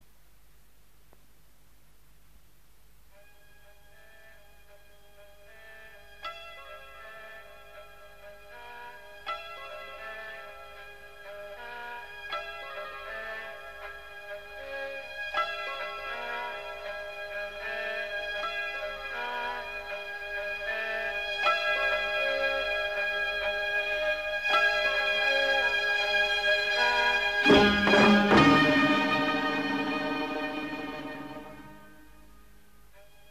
لری